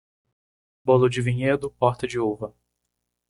Pronounced as (IPA)
/ˈu.vɐ/